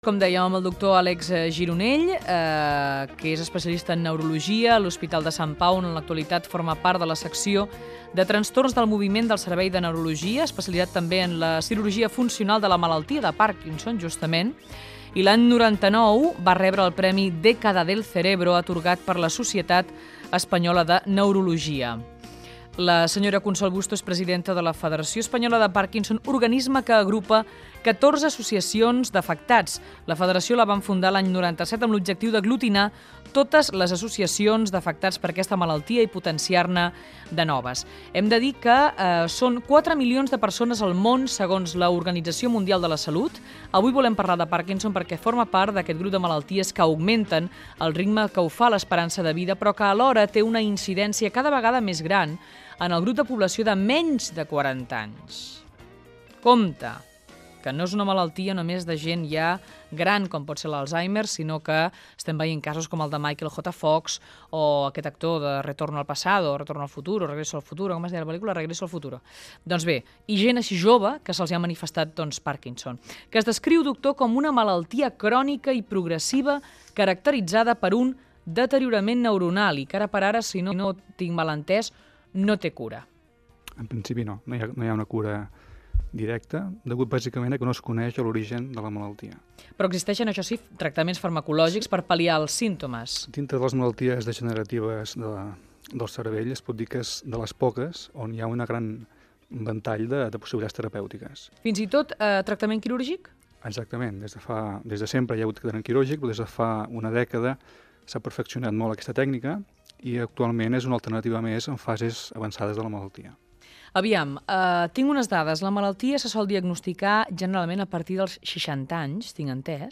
Fragment d'una entrevista
Entreteniment